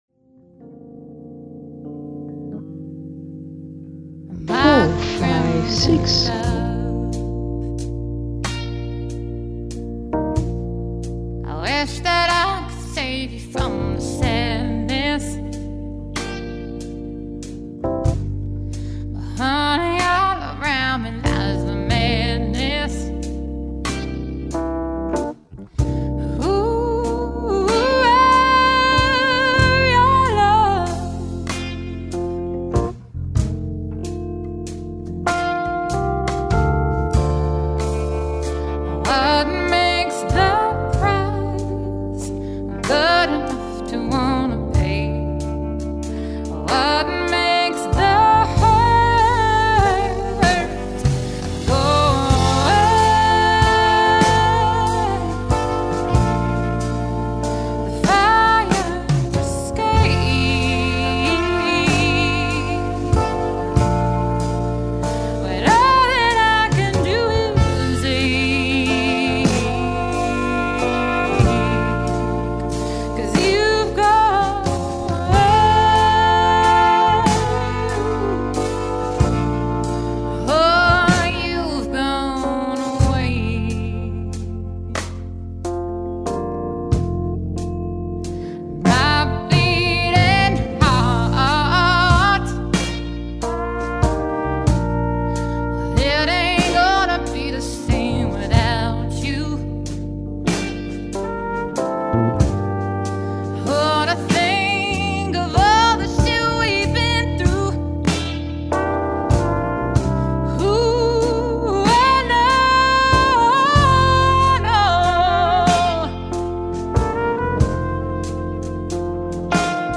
2012_Novice_Rise&Fall_ZENYATTA_WALTZ_count.wav